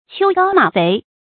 秋高馬肥 注音： ㄑㄧㄡ ㄍㄠ ㄇㄚˇ ㄈㄟˊ 讀音讀法： 意思解釋： 秋高氣爽，馬匹肥壯。